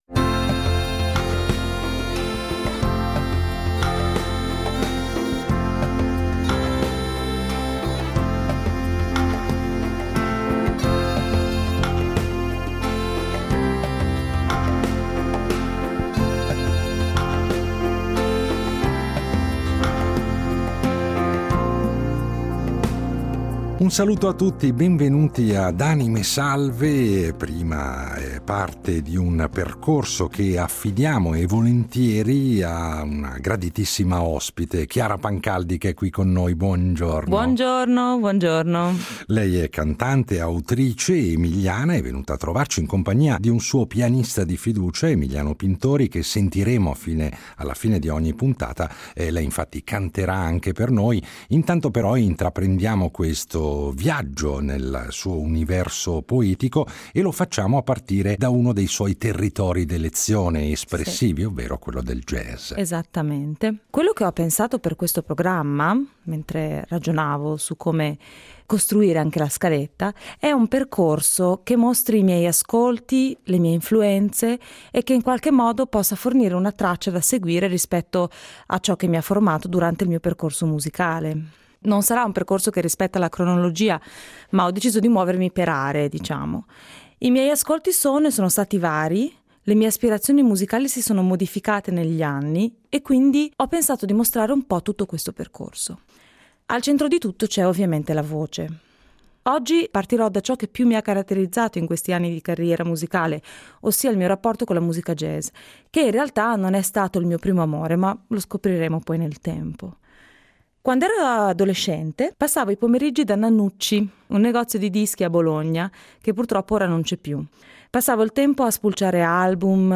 talentuosa cantante bolognese
che ascolteremo in versioni più intime